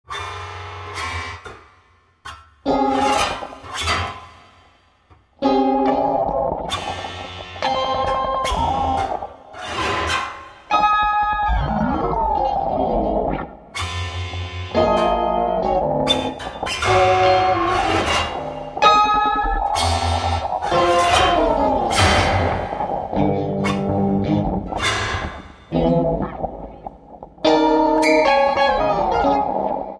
Free improvisation that stretches the limits